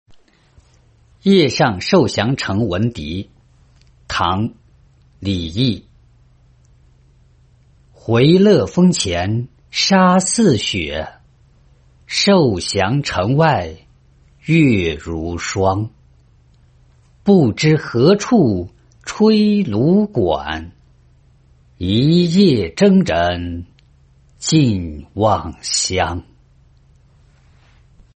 夜上受降城闻笛-音频朗读